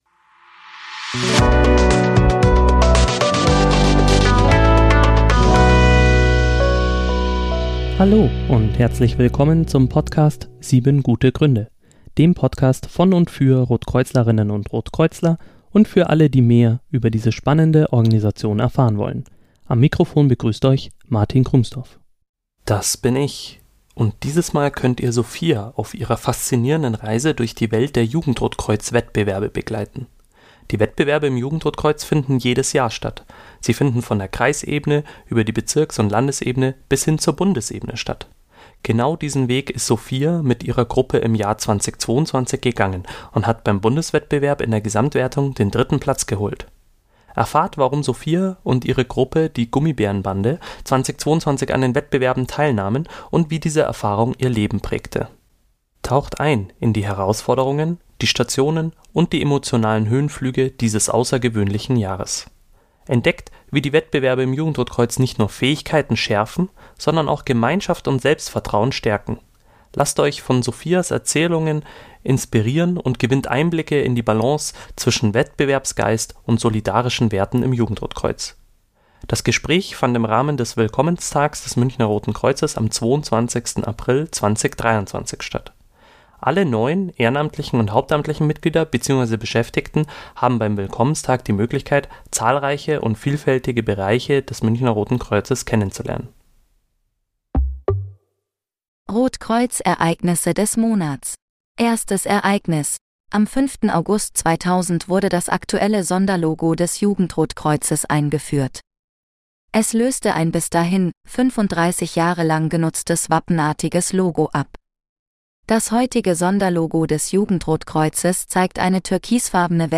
Das Gespräch fand im Rahmen des Willkommenstags des Münchner Roten Kreuzes am 22. April 2023 statt.